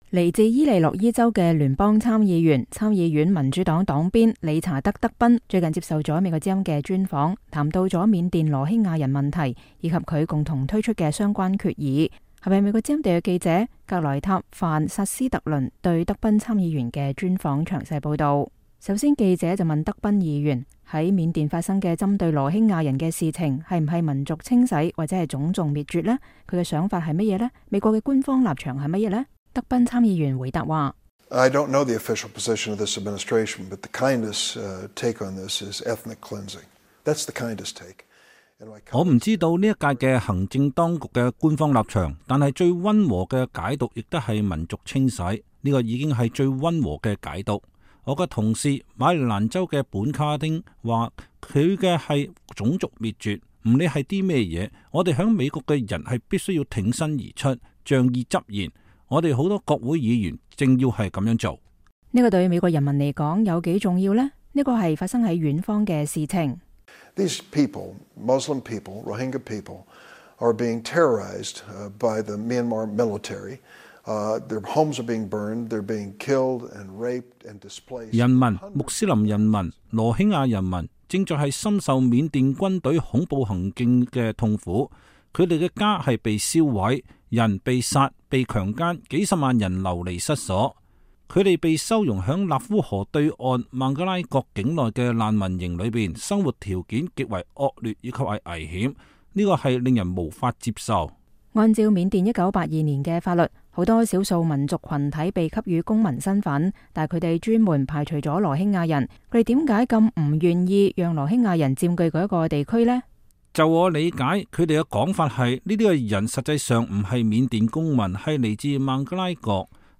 專訪德賓參議員: 美國如何應對羅興亞危機
來自伊利諾伊州的聯邦參議員、參議院民主黨黨鞭理查德·德賓( Senator Dick Durbin (D-IL) )本星期接受了美國之音的專訪，談到了緬甸羅興亞人問題以及他共同推出的相關決議。